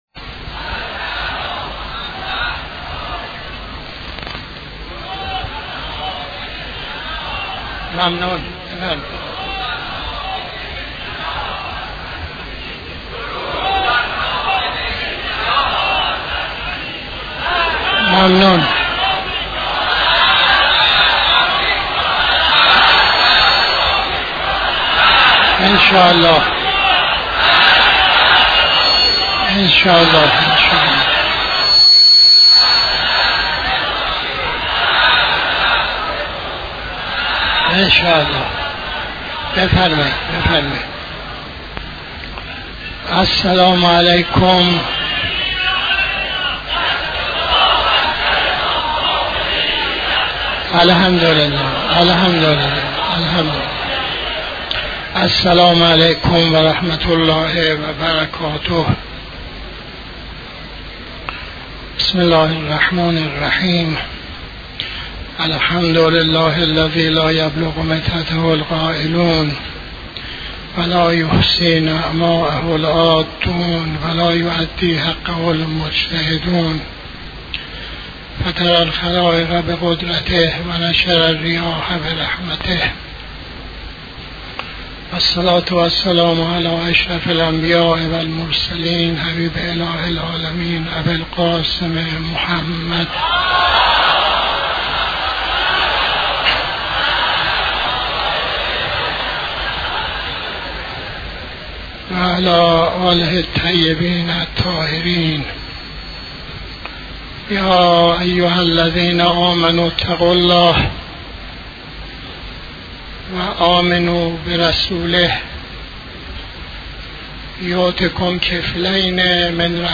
خطبه اول نماز جمعه 21-12-83